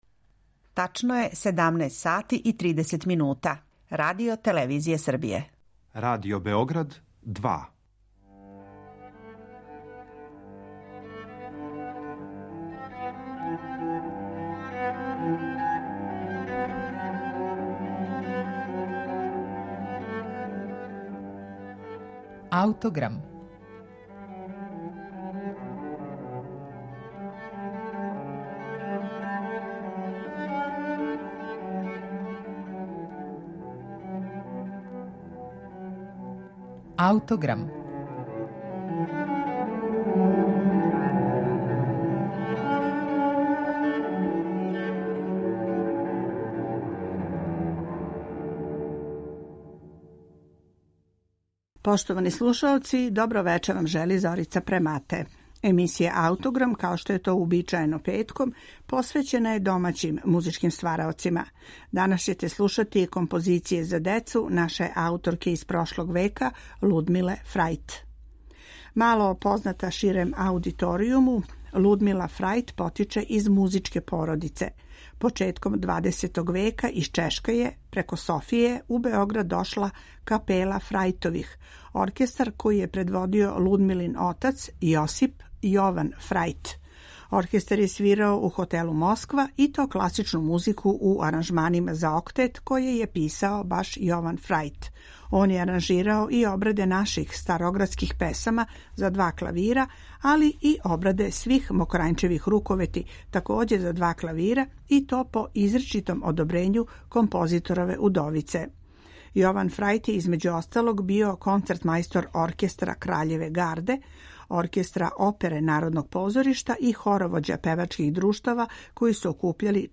симфонијска прича за децу
Солиста на кларинету